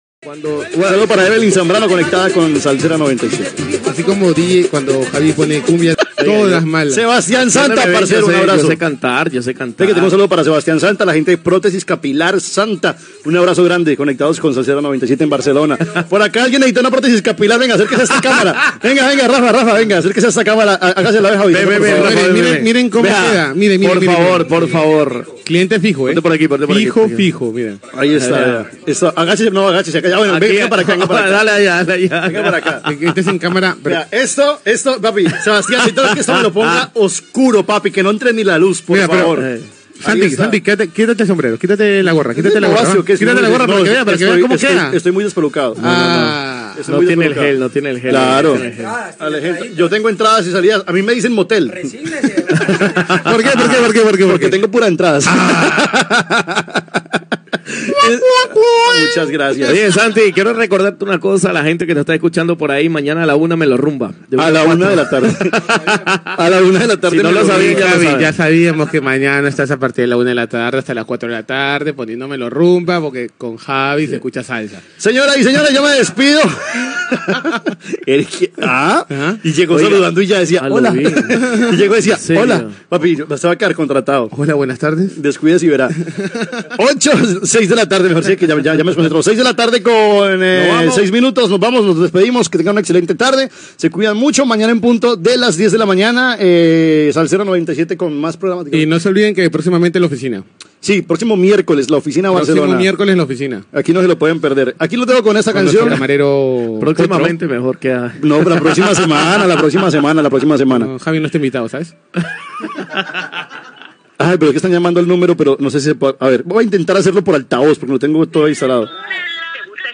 Llatina
Entreteniment
FM